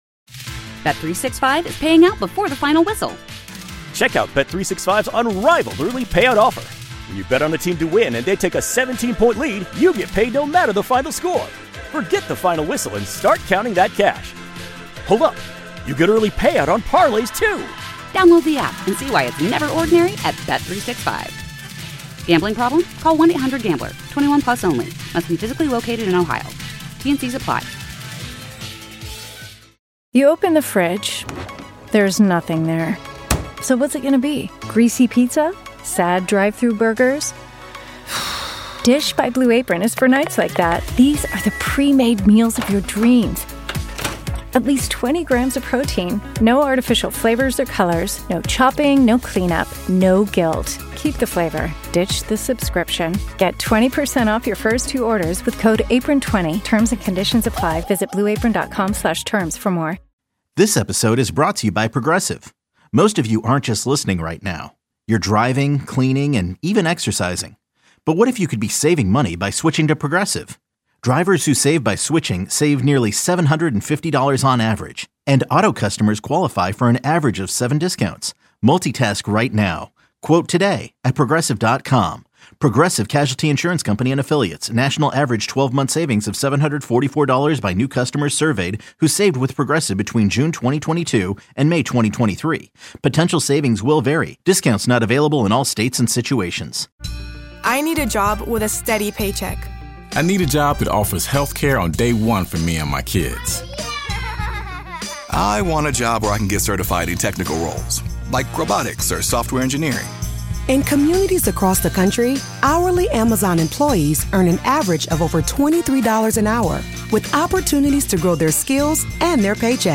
Indulge your inner curiosity with caller driven conversation that makes you feel like you’re part of the conversation—or even better, eavesdropping on someone else’s drama.